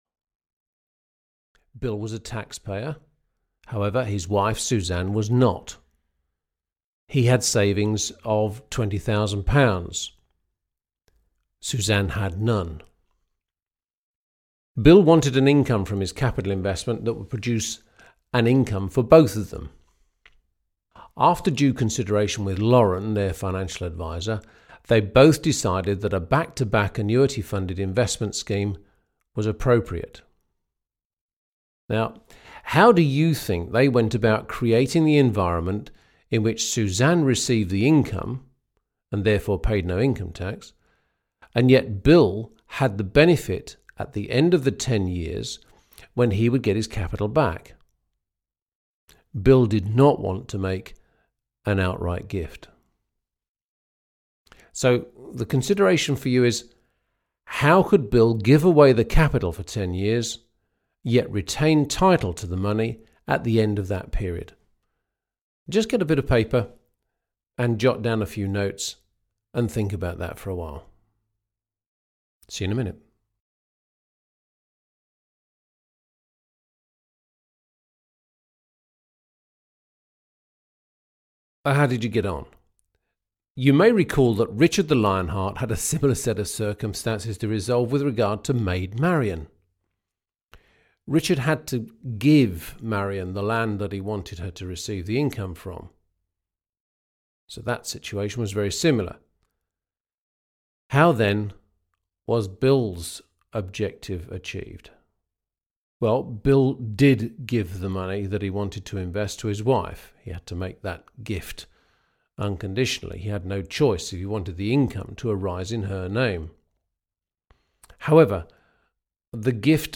Audio knihaTrusts – A Practical Guide 4 (EN)
Ukázka z knihy